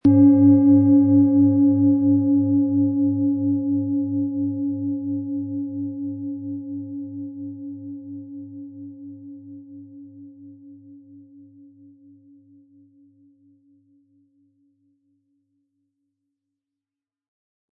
Planetenschale® Reden und Ausdrücken können & Geistige Wachheit mit Merkur, Ø 20,9 cm inkl. Klöppel
Den passenden Schlegel erhalten Sie kostenfrei mitgeliefert, der Schlägel lässt die Schale voll und wohltuend erklingen.
PlanetentonMerkur & DNA (Höchster Ton)
MaterialBronze